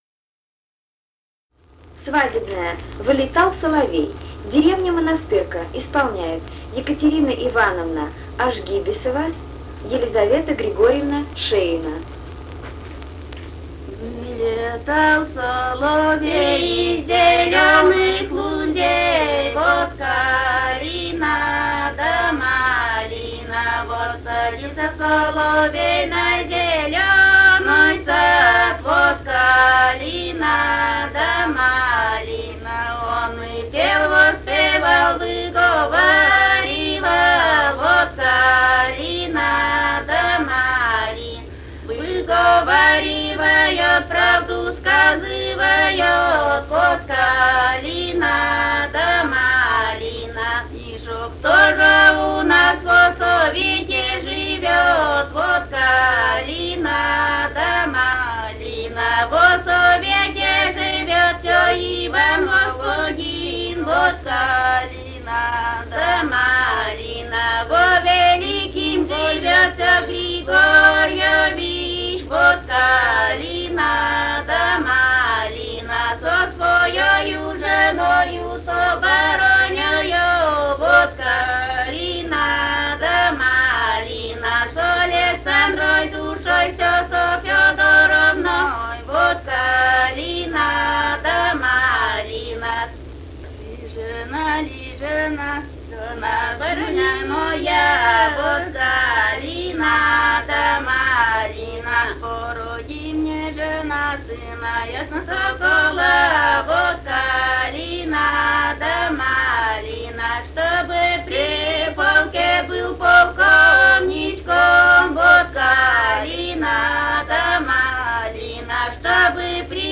Этномузыкологические исследования и полевые материалы
Пермский край, д. Монастырка Осинского района, 1968 г. И1074-20